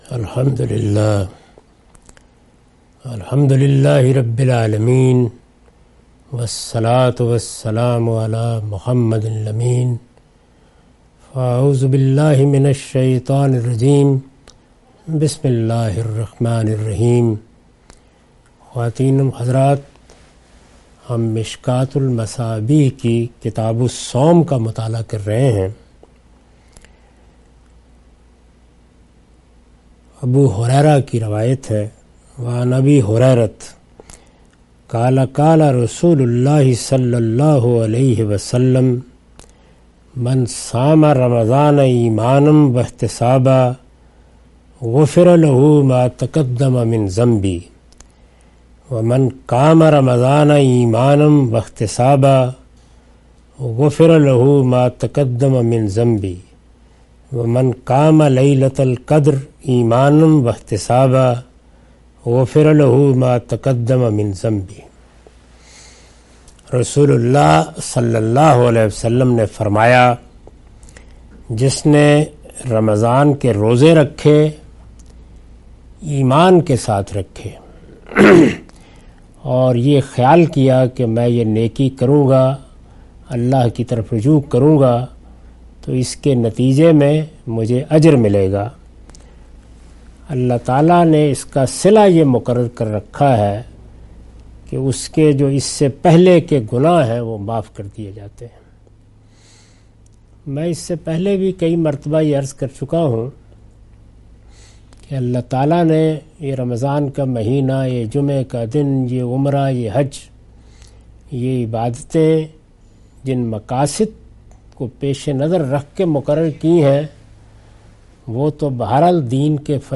Sayings of the Prophet , Questions & Answers